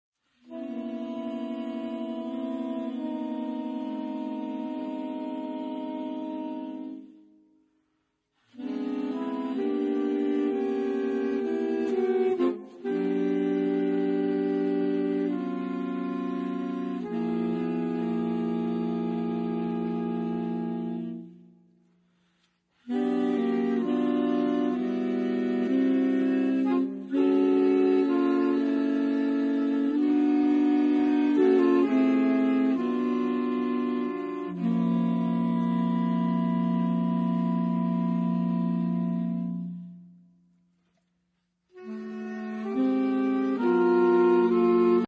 Obsazení: 4 Saxophone (SATBar)